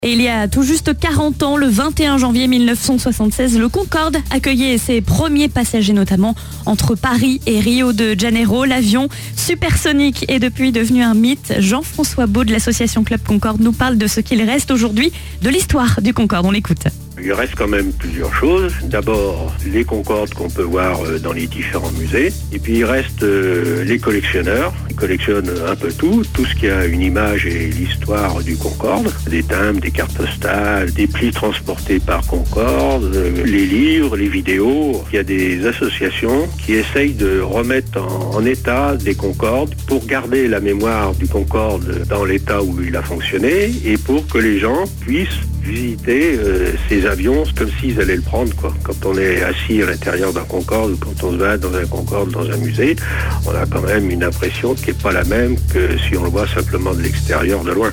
L'interview